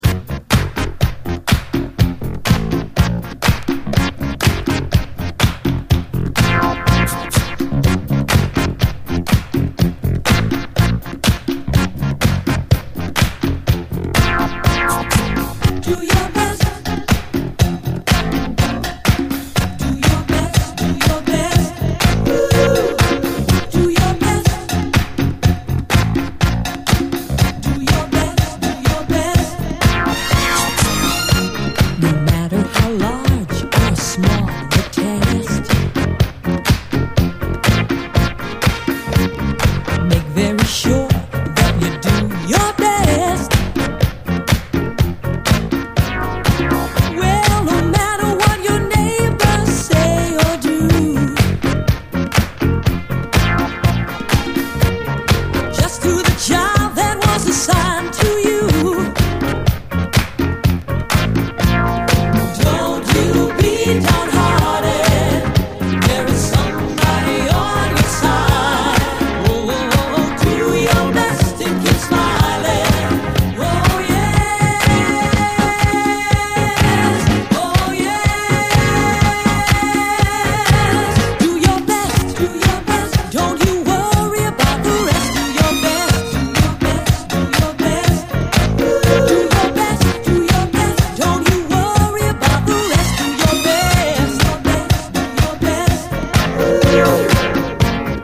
SOUL, DISCO
ヌケのいいシンセの残響がこだまする、華やかなこみ上げ系フロア・クラシック！